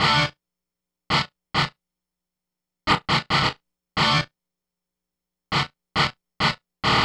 guitar.wav